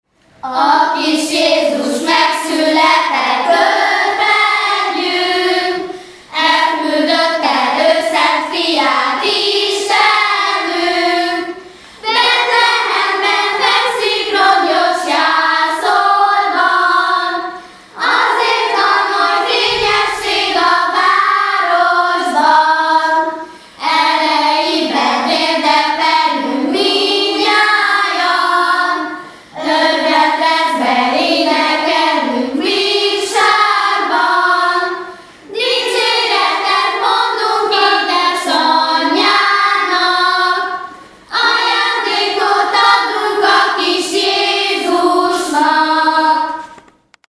Ajándékul fogadjanak el néhány karácsonyi dalt iskolánk gyermekkórusa előadásában.